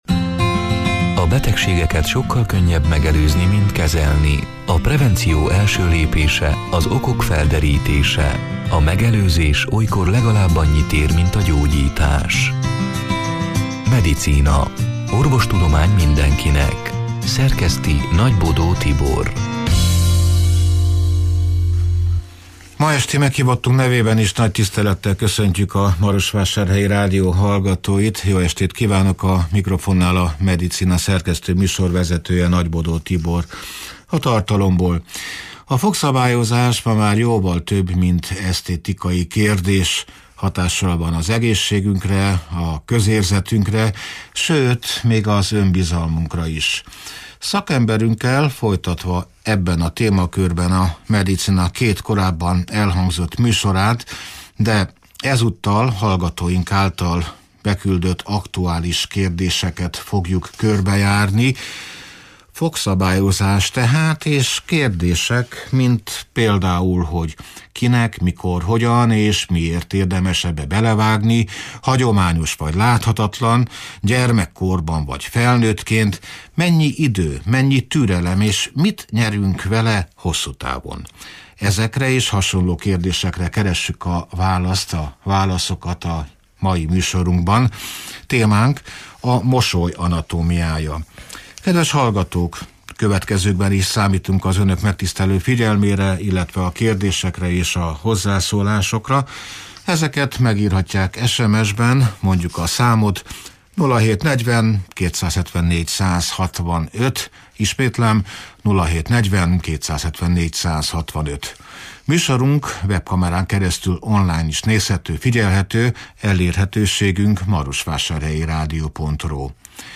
A Marosvásárhelyi Rádió Medicina (elhangzott: 2026. január 21-én, szerdán este nyolc órától élőben) c. műsorának hanganyaga: